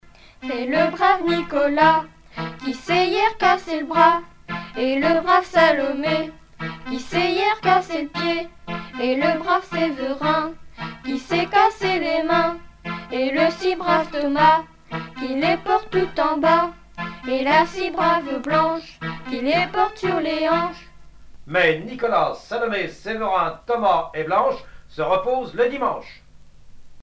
Catégorie Pièce musicale éditée